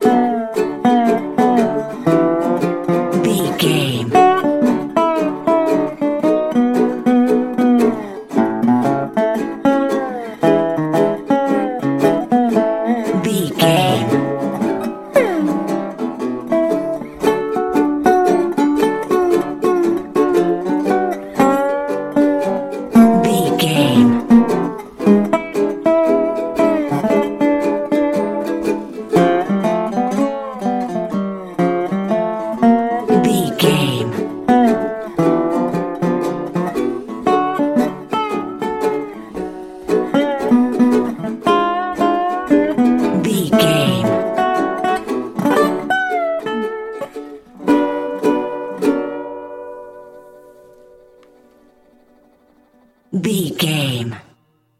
Uplifting
Ionian/Major
acoustic guitar
bass guitar
ukulele
dobro
slack key guitar